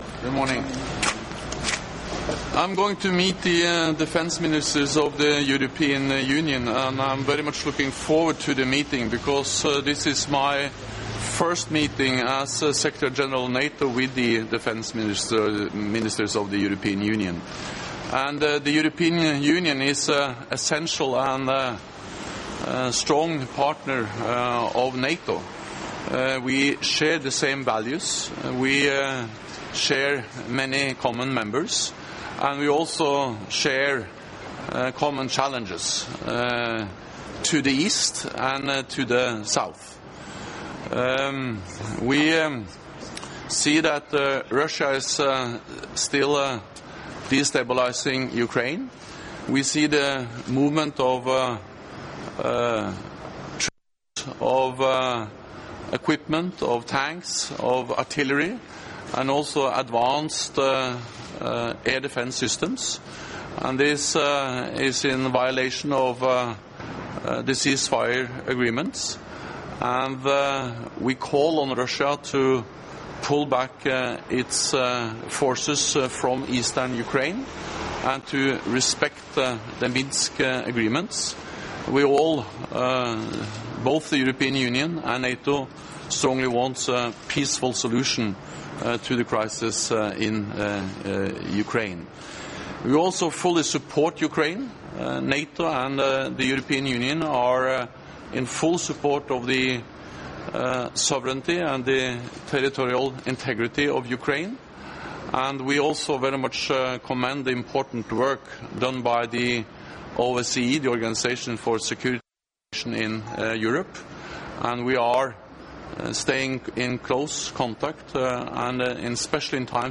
Doorstep statement by NATO Secretary General Jens Stoltenberg upon arrival at European Union Foreign Affairs Council